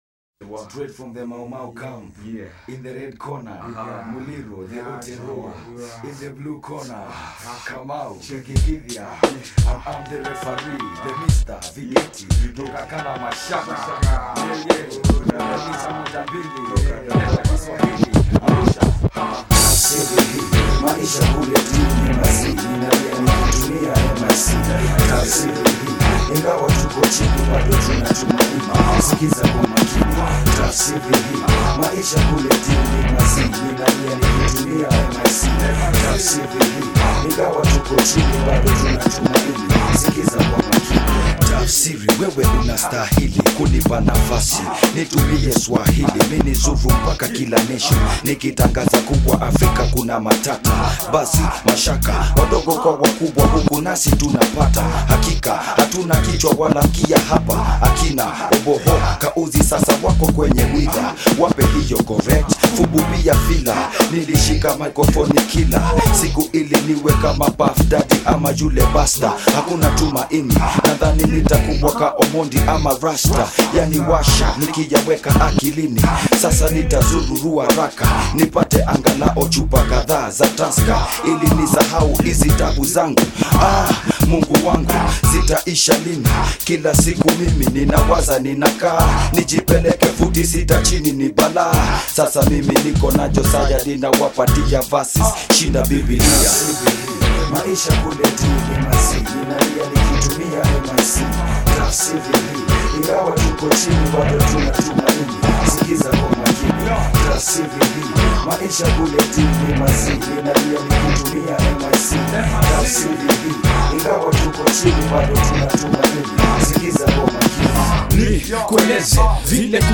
the (un)Official website of Kenya's phattest rap act